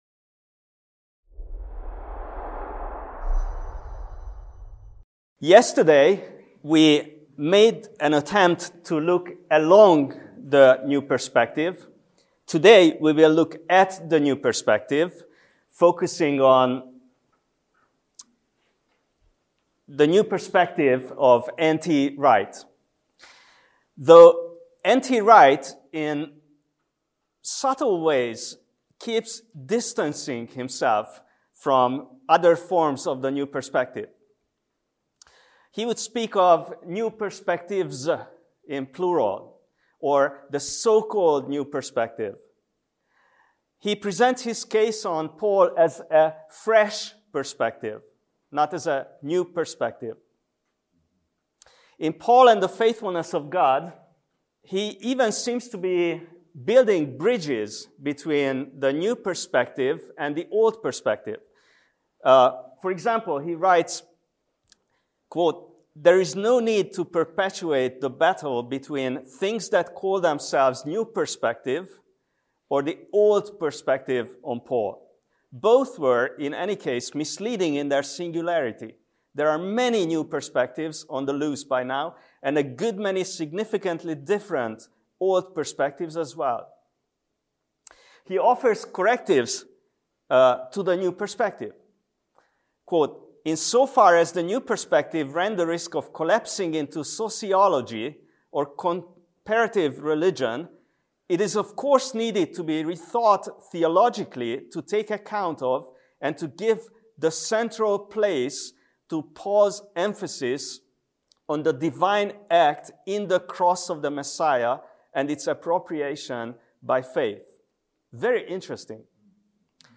What should we make of this new understanding of Paul? In this talk, we attempt to give a fair theological critique of N.T. Wright’s theological architecture.